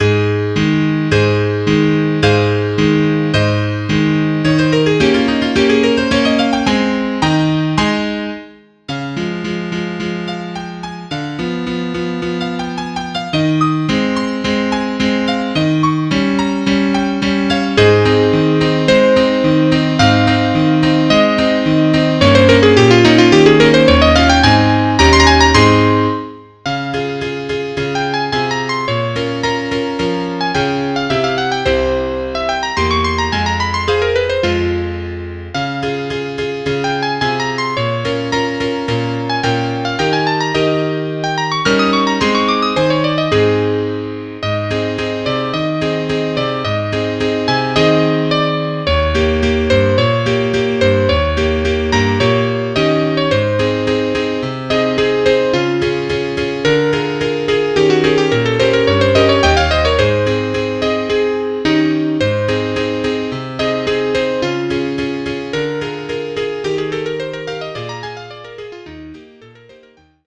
Bravourstück für ein Soloinstrument mit Klavierbegleitung.
Hörbeispiel (Es-Dur):